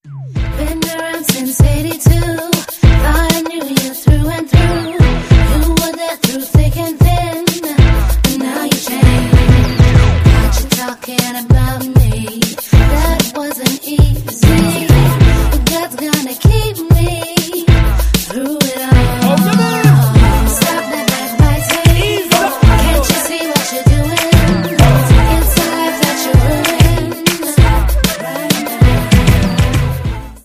• Sachgebiet: Urban